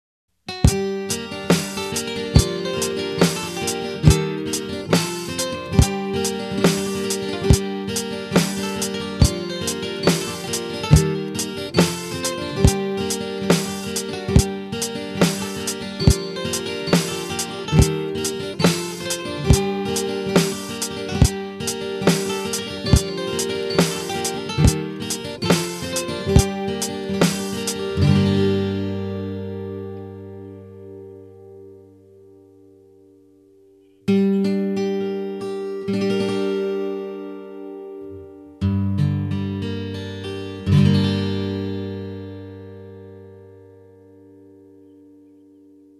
条件は同じっす。S.Yairiの弦も移植して同じ条件で弾いております。
YEは、YFに比べて優しい柔らかい音ってか、ボディーが大きい分
深いってかサスティーンが効いてますわ。